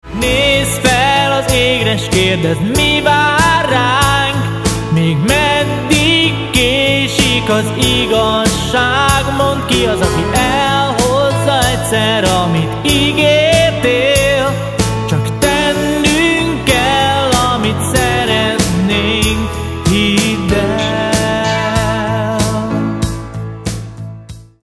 billentyűs hangszerek
gitárok
sax
bass